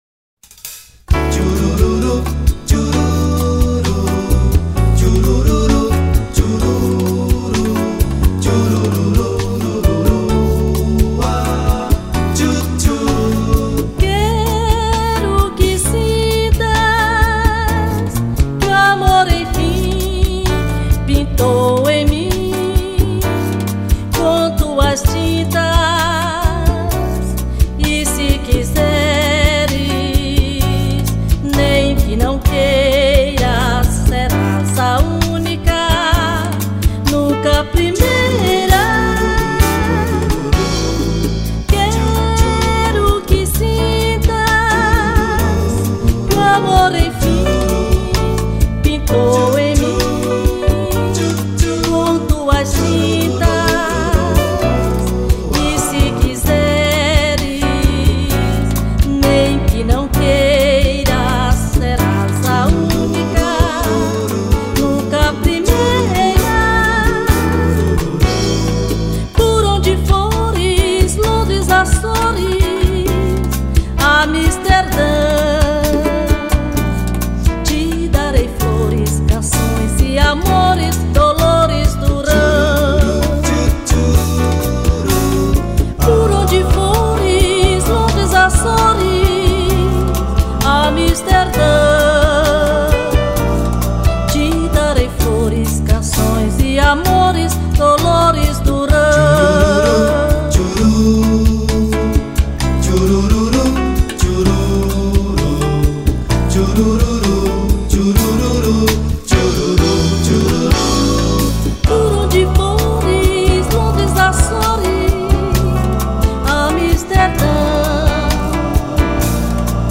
297   02:44:00   Faixa:     Bolero